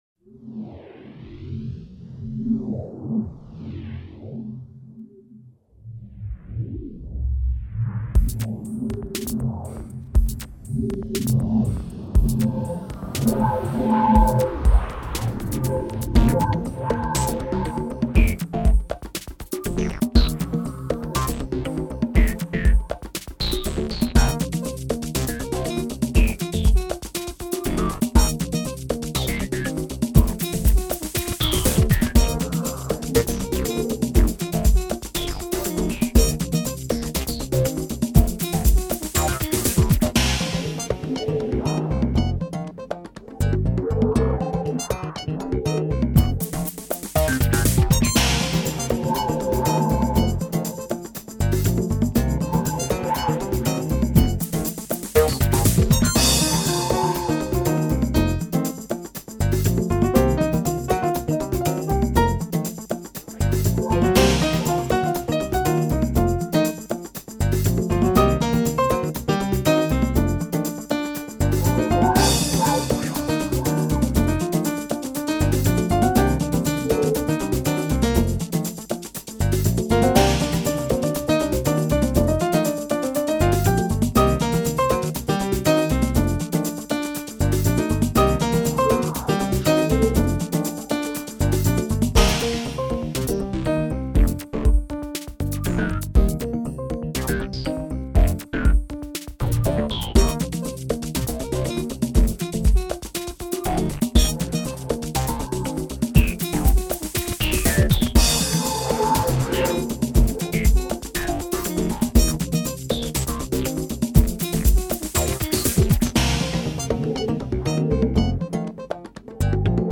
拉丁爵士乐